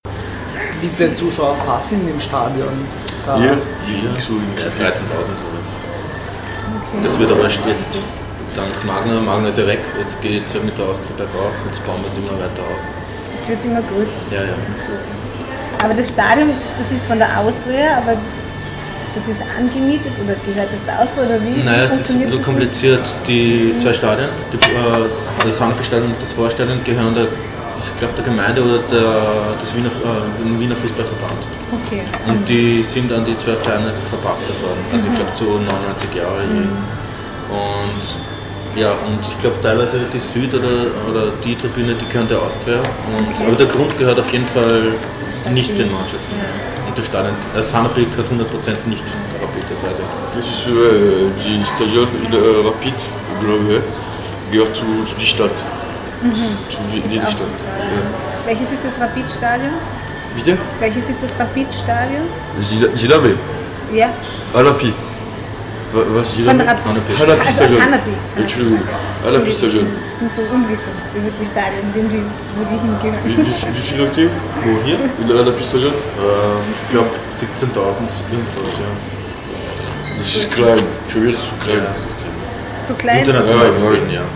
Interview mit zwei Fußball-Fans
Das Interview wurde am 14.12.2009 in Wien geführt.